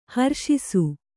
♪ harṣisu